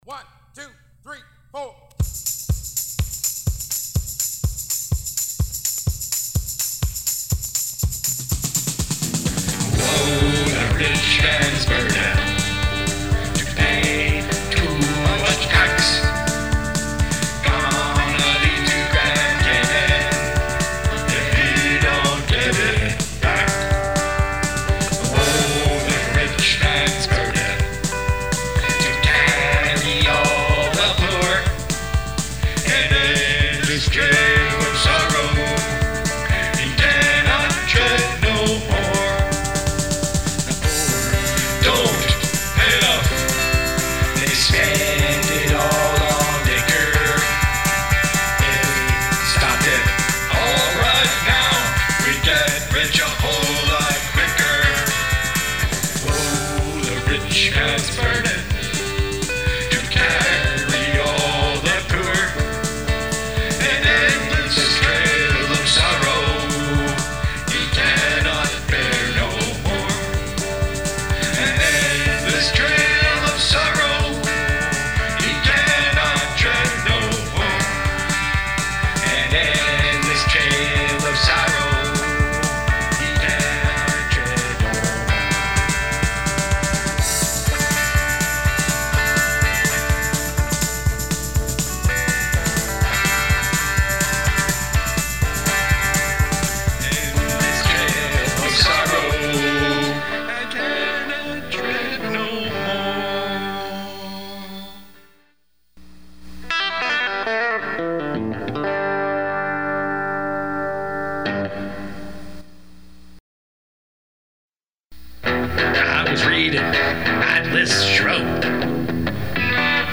Rock 'n' Roll